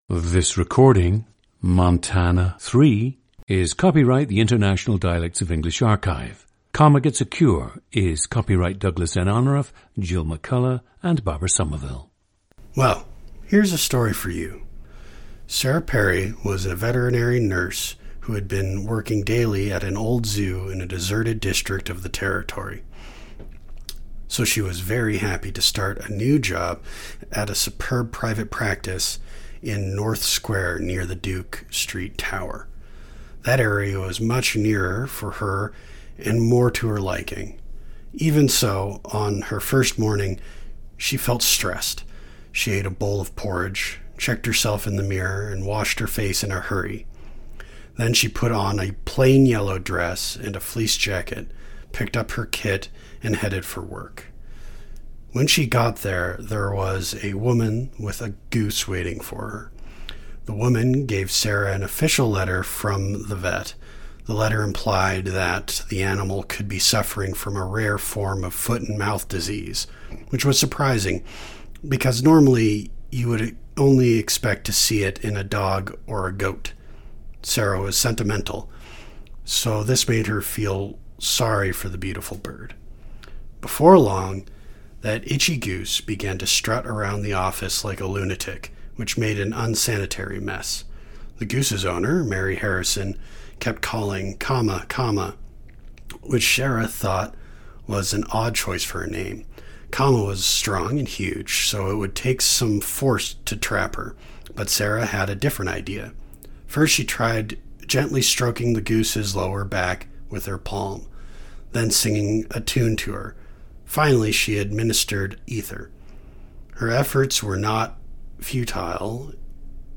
PLACE OF BIRTH: Helena, Montana
GENDER: male
The subject’s father had a mild New York accent.
• Recordings of accent/dialect speakers from the region you select.